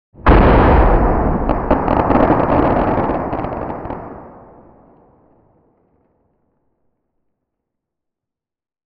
bloodclot-explode.wav